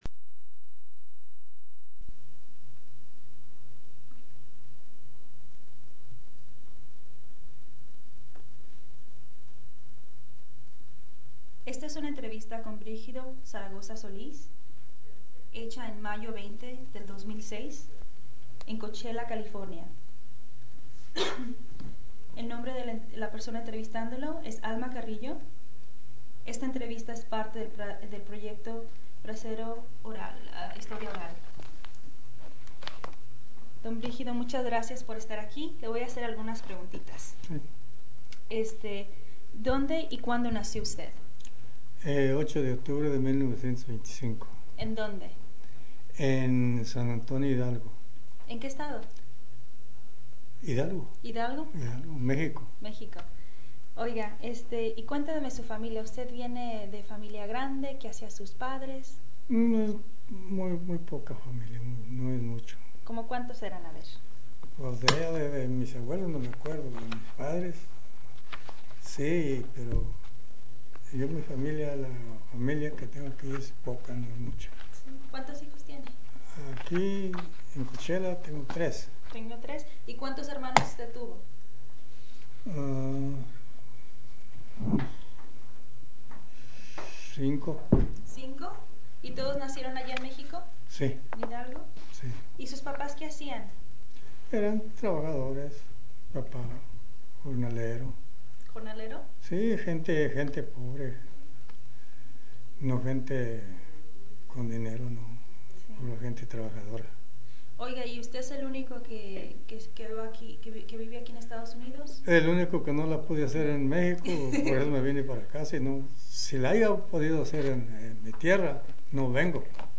Summary of Interview